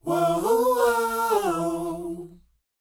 WHOA F AD.wav